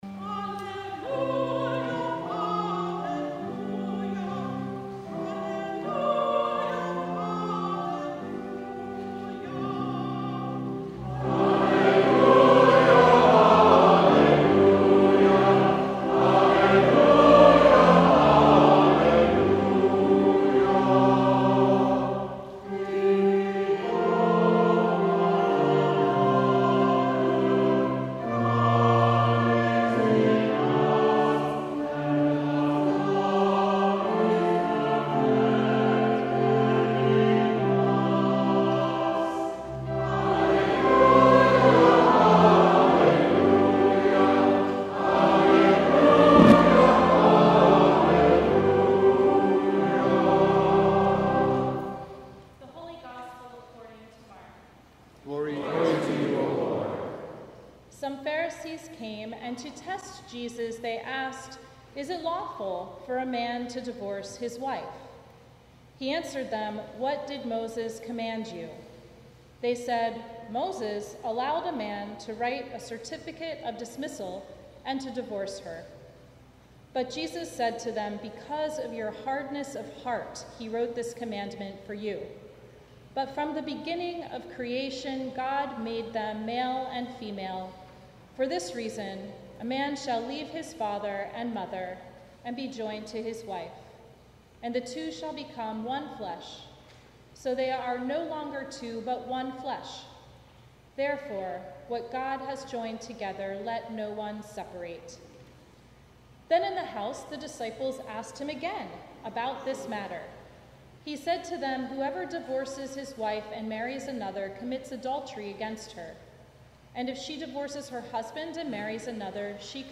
Sermon from the Twentieth Sunday After Pentecost